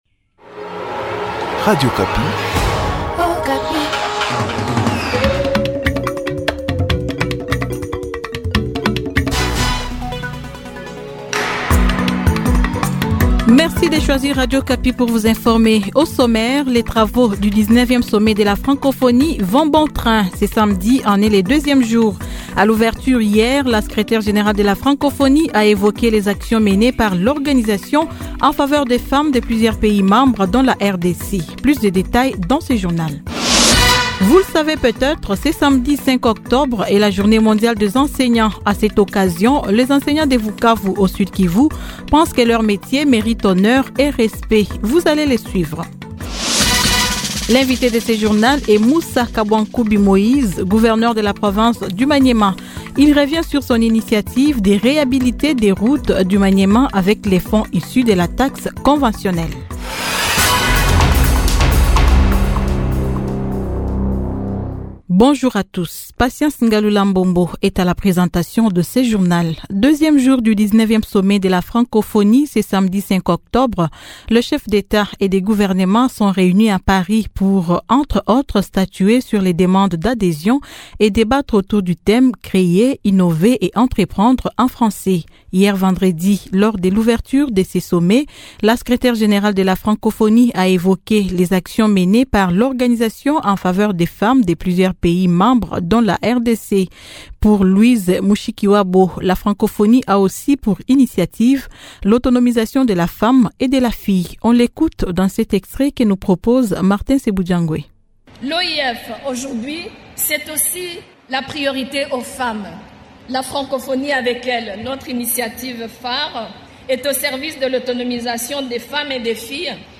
Journal 15H00